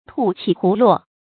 兔起鶻落 注音： ㄊㄨˋ ㄑㄧˇ ㄏㄨˊ ㄌㄨㄛˋ 讀音讀法： 意思解釋： 兔子剛起跑；鶻就猛撲下去。